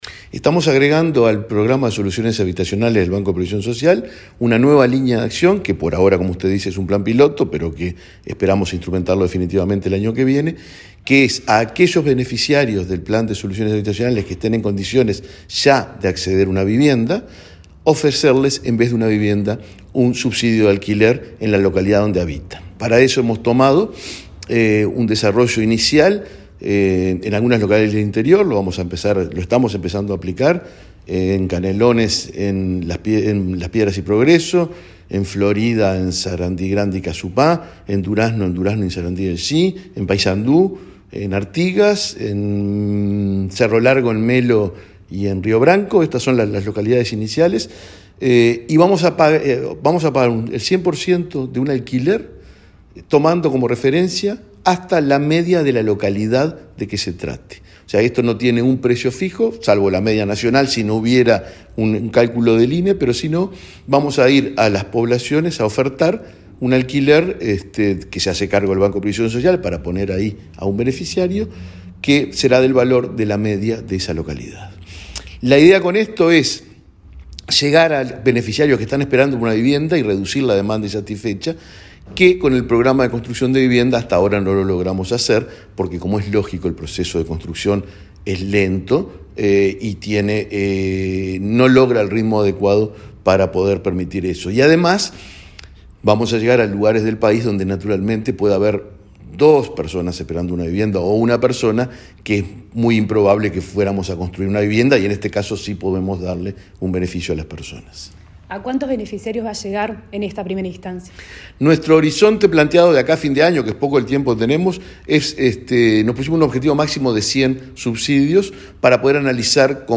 Entrevista al presidente del BPS, Alfredo Cabrera